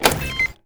LiftButton.wav